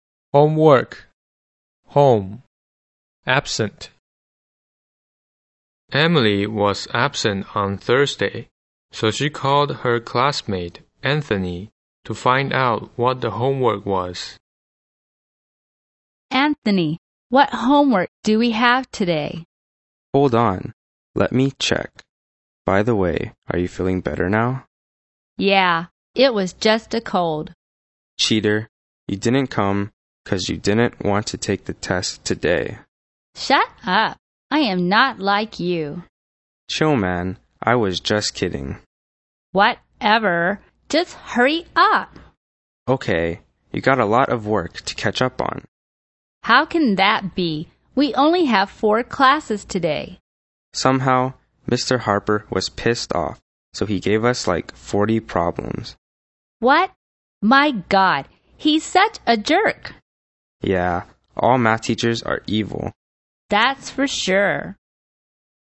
EPT美语 家里（对话） 听力文件下载—在线英语听力室